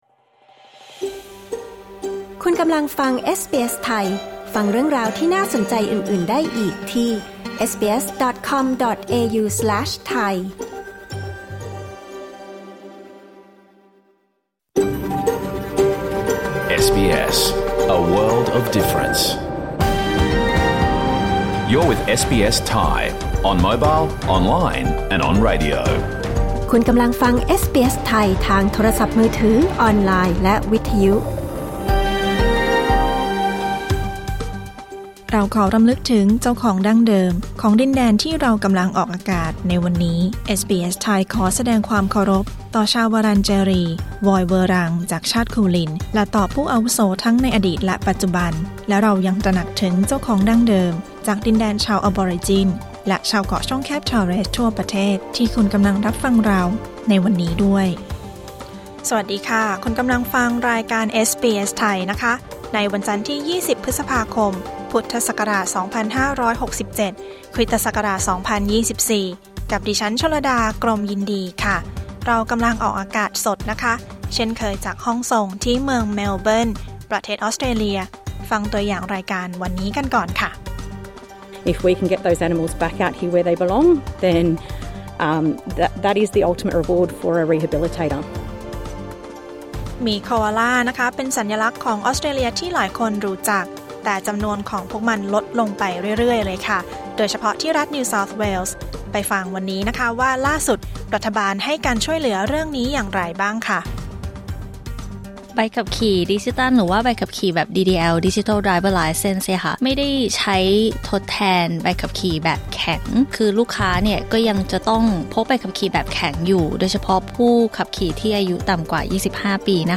รายการสด 20 พฤษภาคม 2567